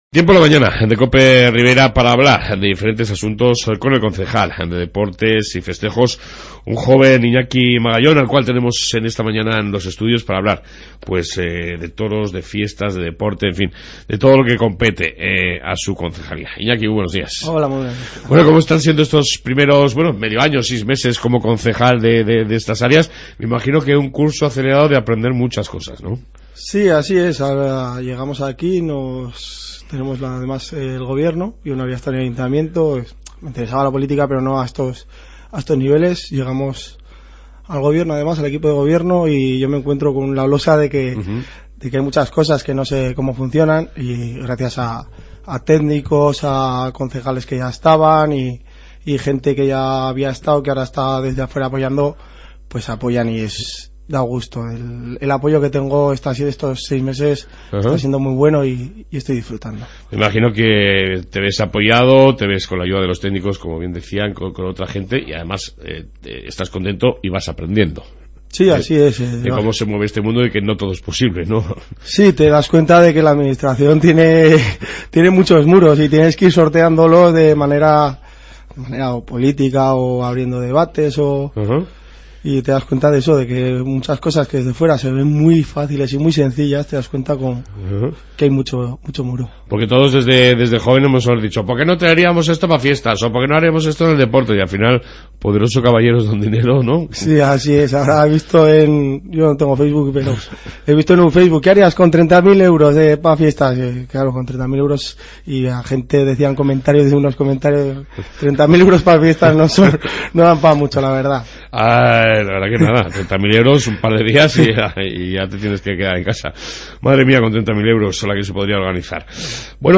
Redacción digital Madrid - Publicado el 29 ene 2016, 17:23 - Actualizado 16 mar 2023, 09:47 1 min lectura Descargar Facebook Twitter Whatsapp Telegram Enviar por email Copiar enlace En esta 2ª parte Hemos podido charlar tranquilamente con el nuevo concejal de Deporte y Festejos Iñaki Magallón de muchos asuntos de actualidad.